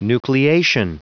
Prononciation du mot nucleation en anglais (fichier audio)
Prononciation du mot : nucleation